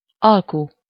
Ääntäminen
IPA: /ˈɒlku/